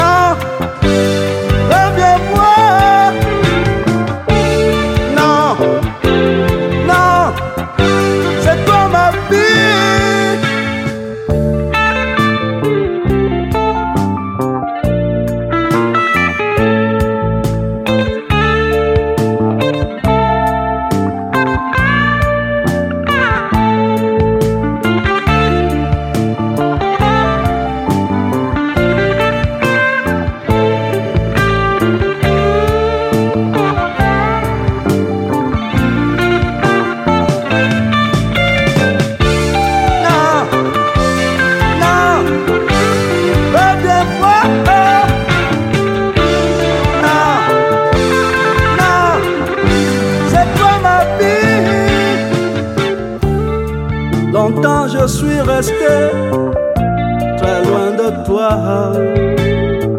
Funk Outernational Africa